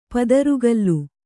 ♪ padaru gallu